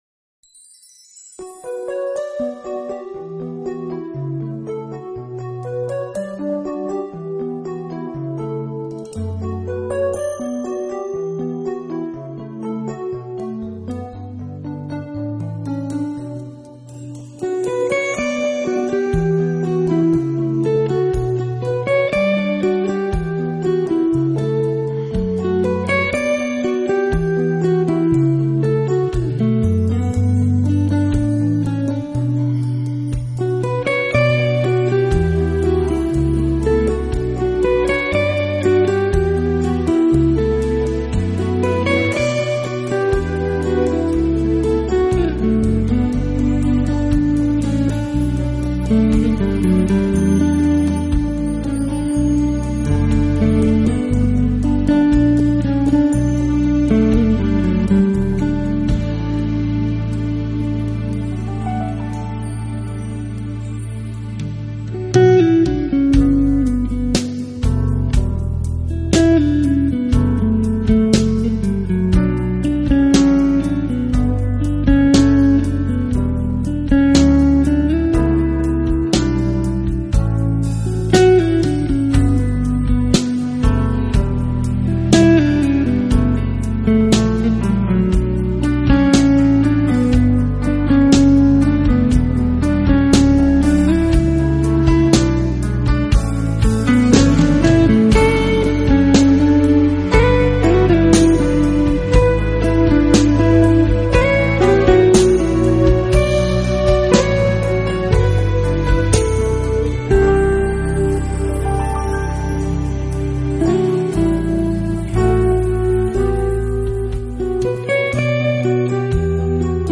音乐类型：Jazz
Smooth  Jazz